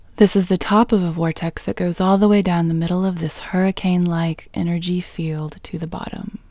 IN – the Second Way – English Female 13